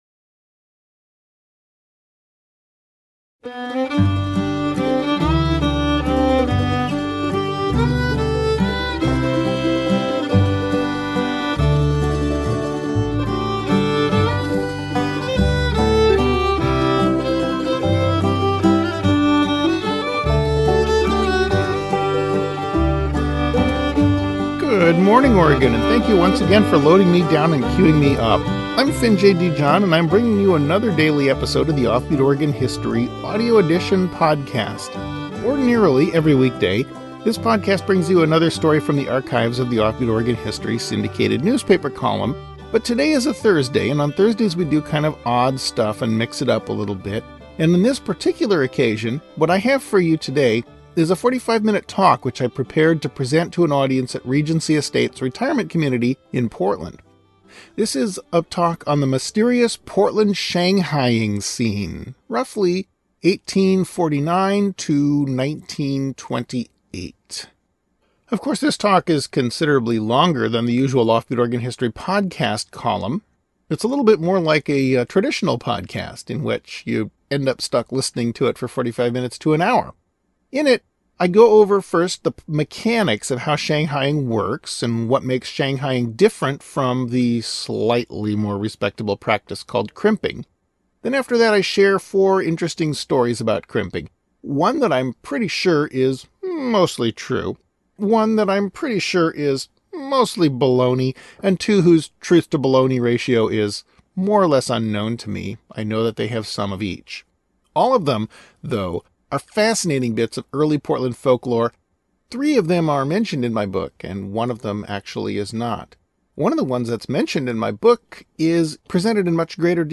Editor’s note: This is a 45-minute talk which I prepared to present to an audience at Regency Estates Retirement Community in Portland. In it, I go over the mechanics of how shanghaiing works, what makes shanghaiing different from the (slightly) more respectable practice called “crimping,” and then share four interesting stories about crimping — one that I’m pretty sure is (mostly) true, one that I’m pretty sure is (mostly) baloney and two whose truth-to-baloney ratio is unknown to me.